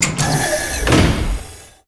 DoorClose5.wav